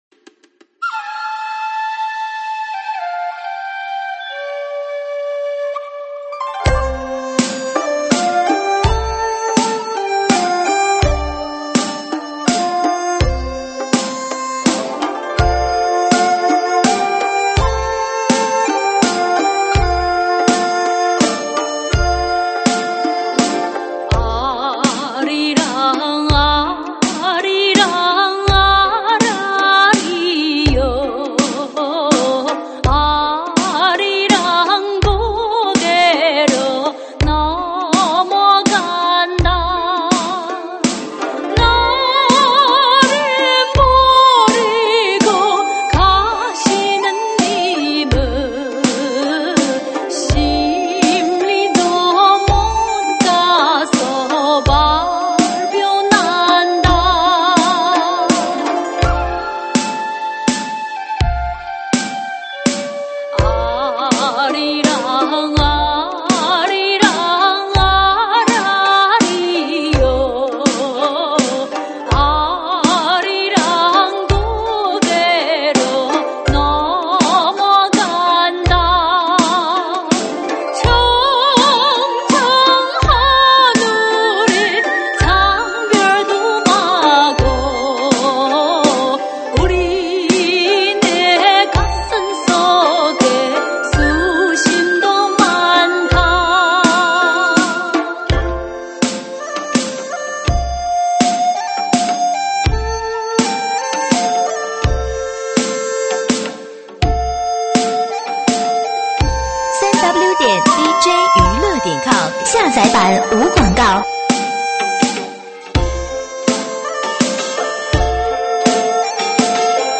收录于(慢三)